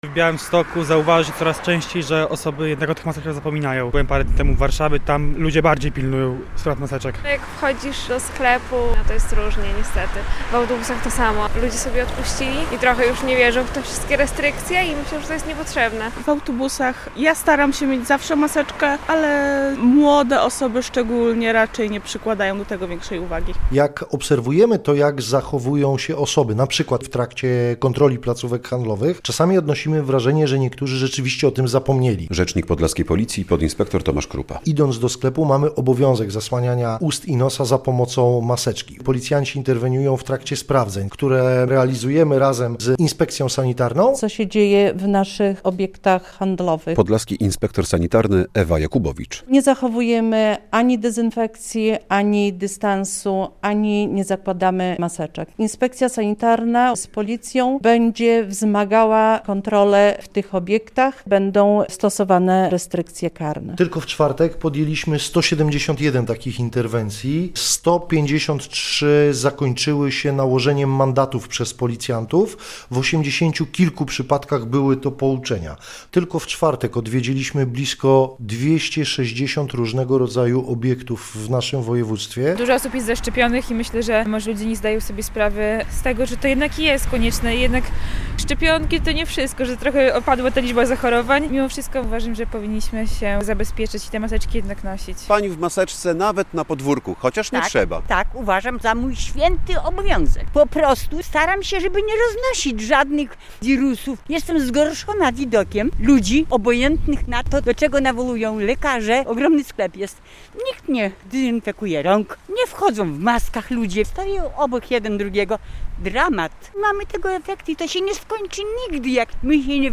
relacja
Białostoczanie, z którymi rozmawialiśmy mówią, że teraz mało osób zakrywa usta i nos w sklepach czy autobusach.